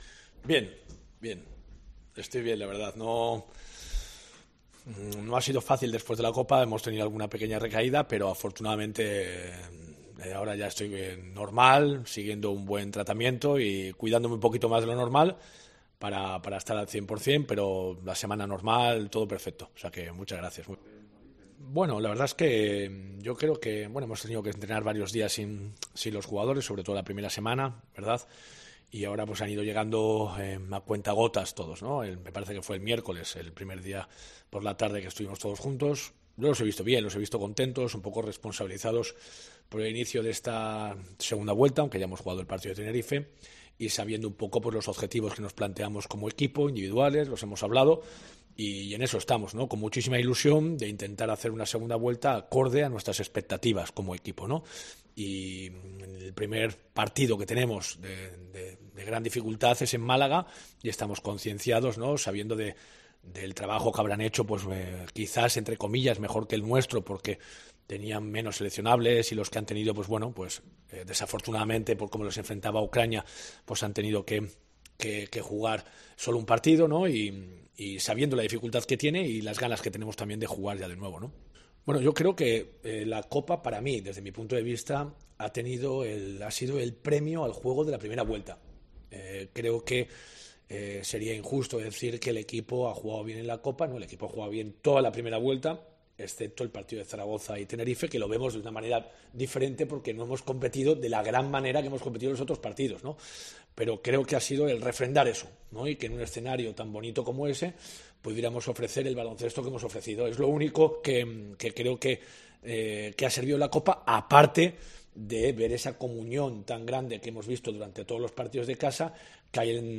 El técnico universitario ha comparecido en rueda de prensa en la víspera del encuentro de la vigésimo tercera jornada del campeonato que el UCAM CB disputará a las seis de la tarde en el Palacio de los Deportes José María Martín Carpena de Málaga.